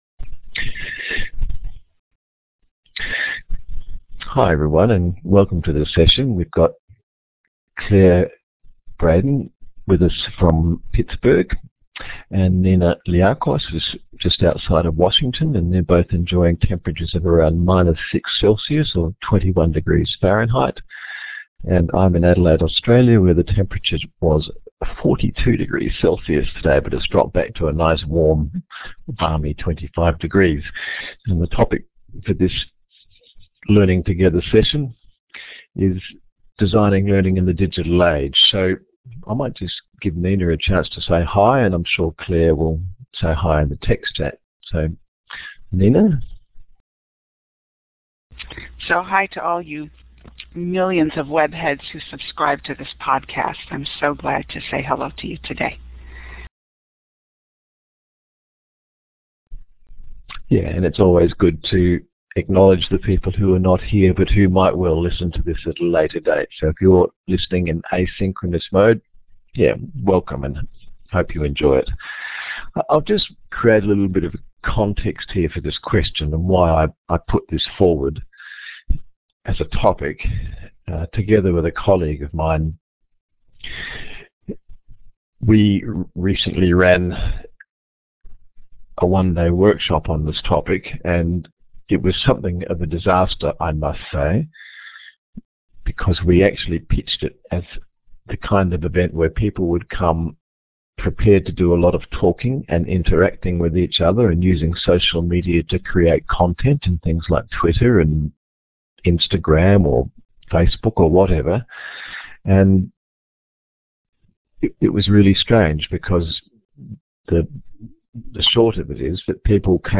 This presentation was planned as an open and unscripted discussion of the results of a Worldwide OER survey conducted earlier this year, presented at the EuroCALL CMC & teacher Education SIG workshop in Bologna, and at CALICO 2012 in South Bend.